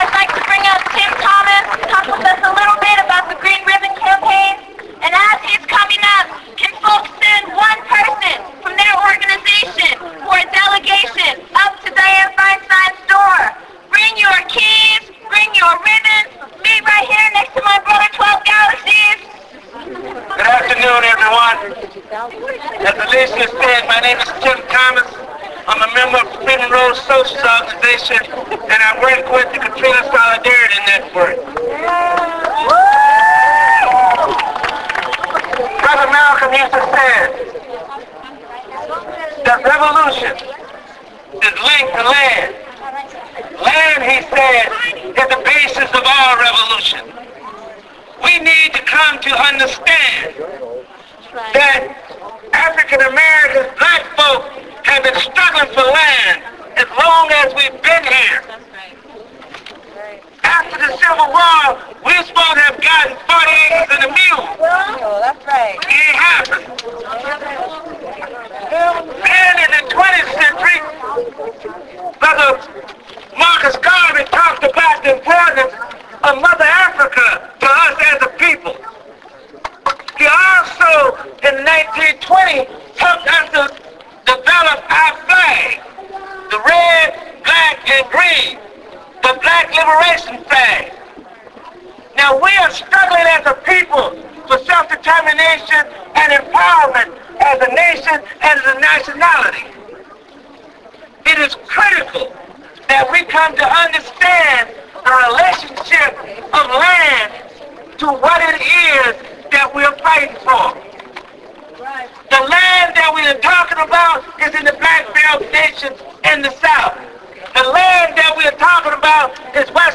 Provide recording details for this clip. On top a hill overlooking San Francisco's bay, hundreds of us came together in an effort to hold Senator Dianne Feinstein accountable, stand in solidarity with people of the Gulf Coast and the world, mourn those lost, and celebrate resistance.